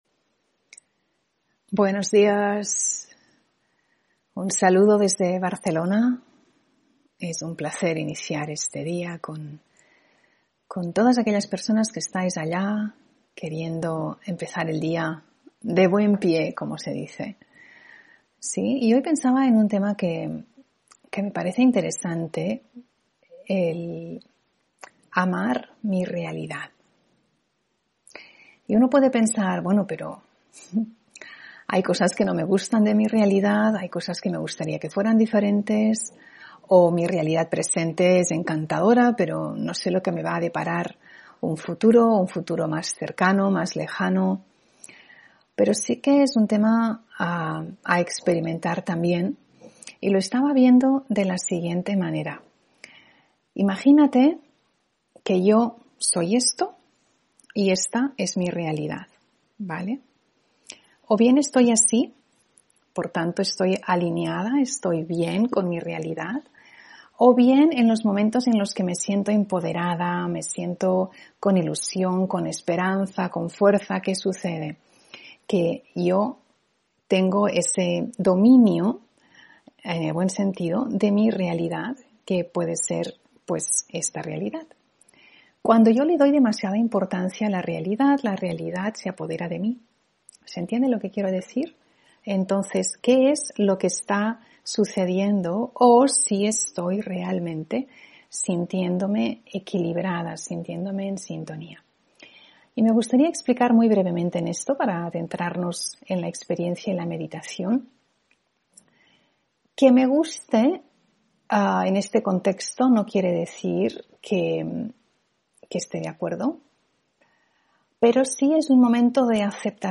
Meditación y conferencia: Mente clara, corazón limpio (3 Diciembre 2022)